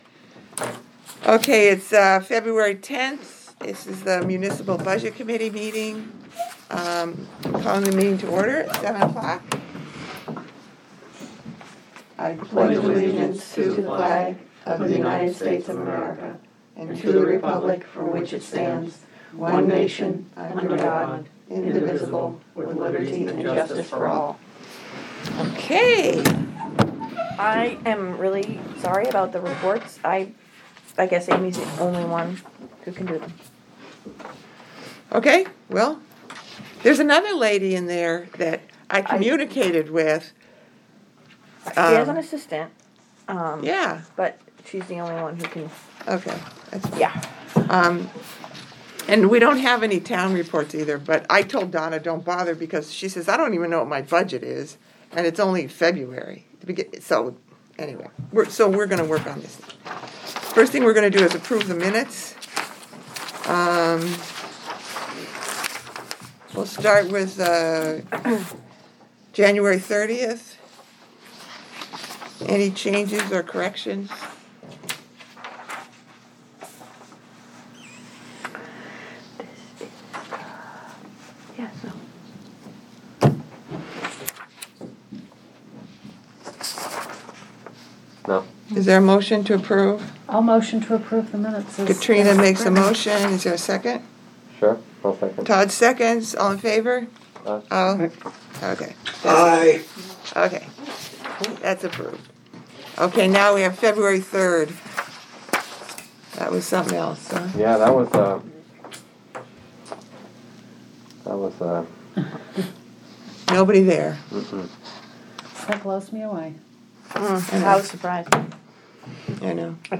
Audio recordings of committee and board meetings.
Budget Committee Meeting